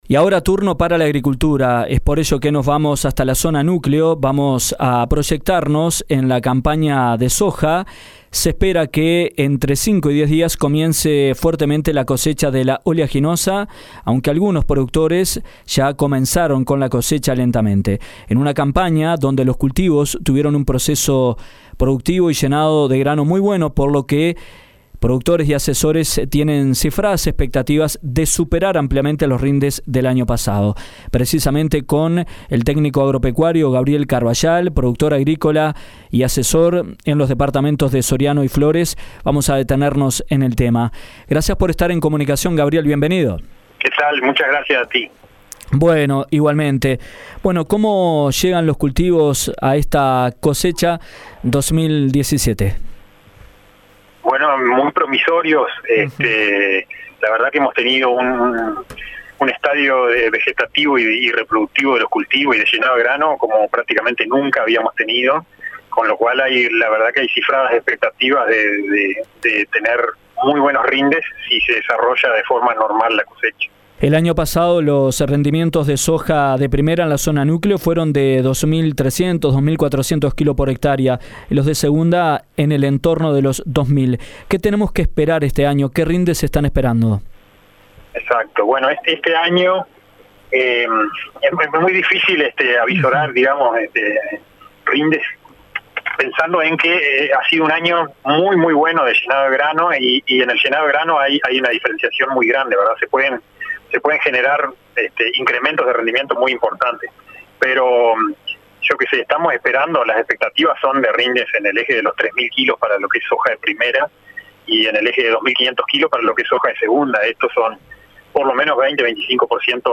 En entrevista con Dinámica Rural, el Téc.